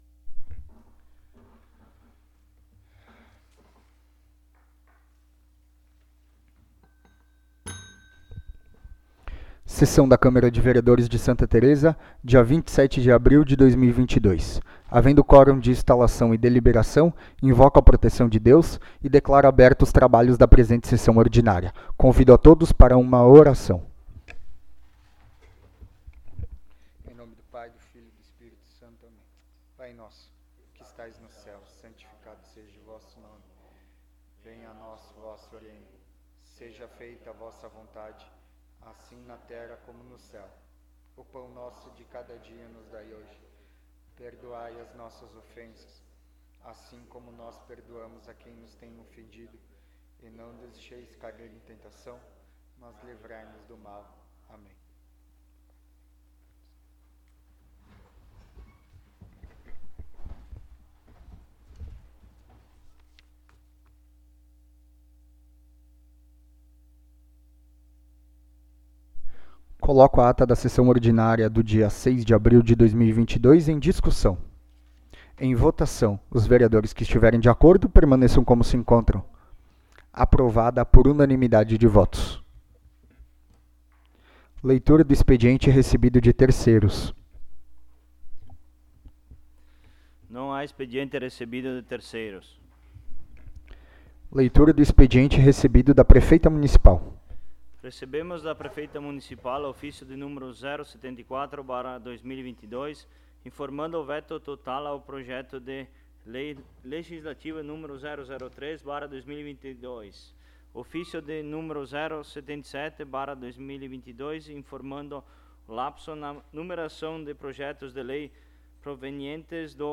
Áudio da Sessão
Local: Câmara Municipal de Vereadores de Santa Tereza